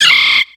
Cri de Balignon dans Pokémon X et Y.